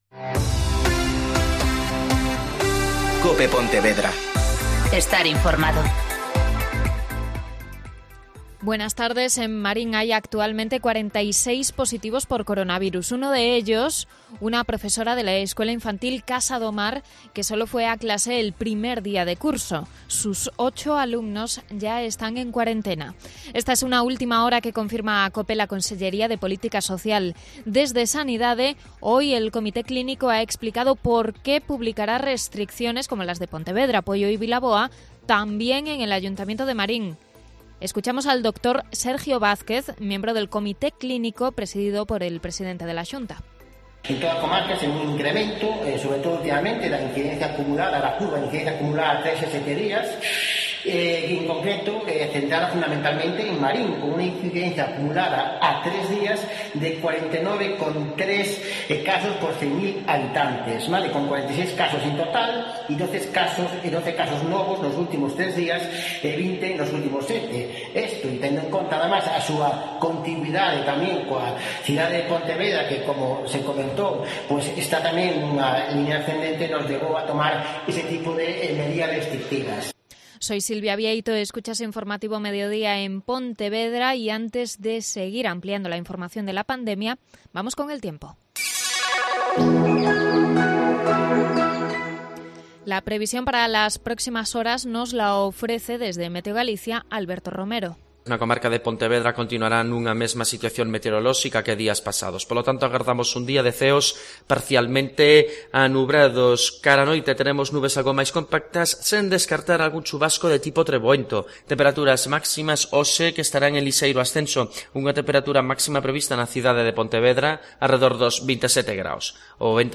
Mediodía COPE Pontevedra (Informativo 14:20h)